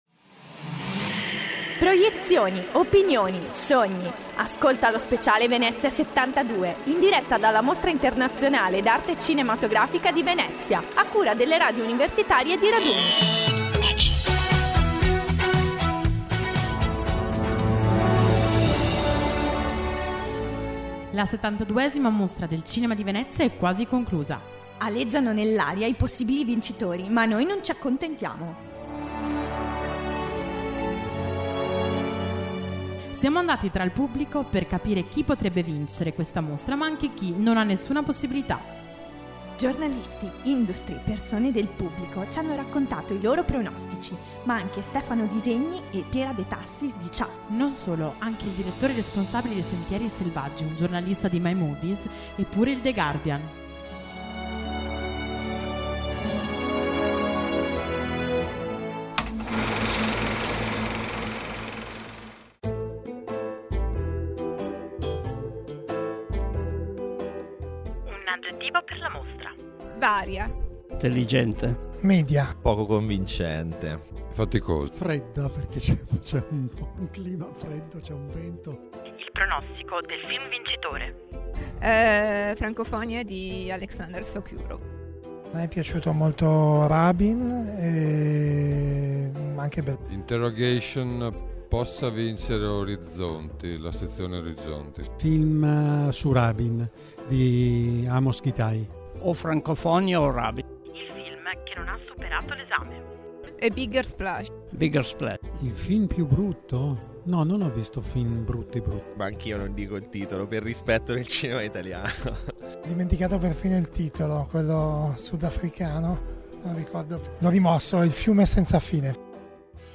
In attesa di conoscere quello che ha deciso la giuria presieduta da Alfonso Cuarón, siamo andate in giro per il Movie village a molestare giornalisti, industry e pubblico chiedendo un aggettivo per descrivere la mostra, il film che potrebbe vincere e quello più brutto.